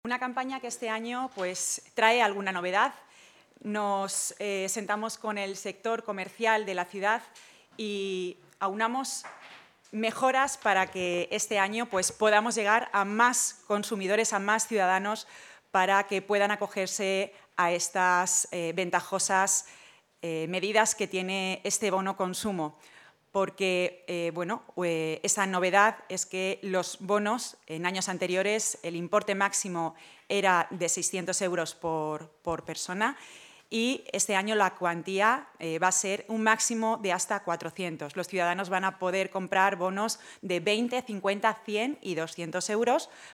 Cortes-audio-Concejala-comercio.mp3